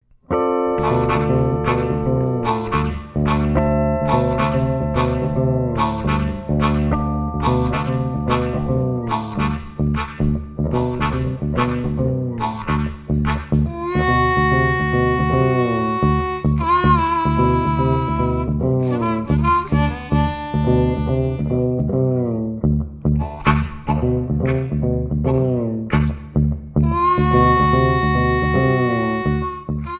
Lap Slide Steel Guitar
Harmonica
A lap slide guitar and a harmonica.
an instrumental blues duet